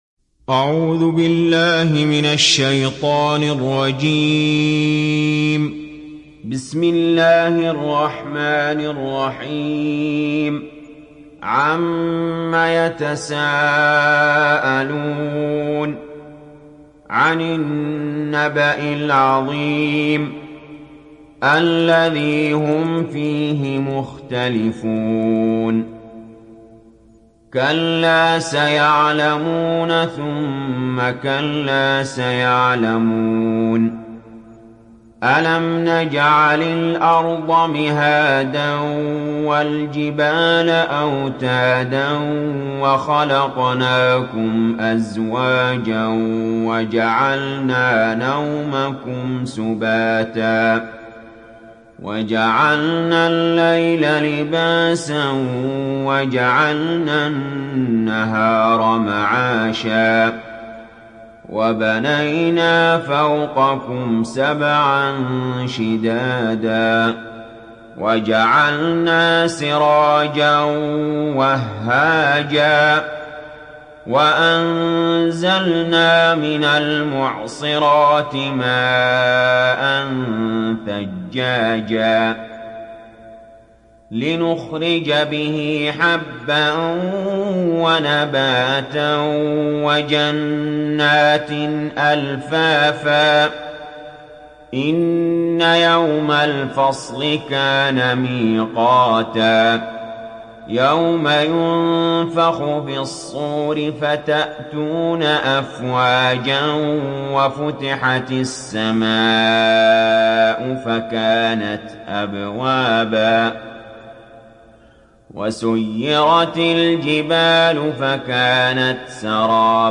تحميل سورة النبأ mp3 بصوت علي جابر برواية حفص عن عاصم, تحميل استماع القرآن الكريم على الجوال mp3 كاملا بروابط مباشرة وسريعة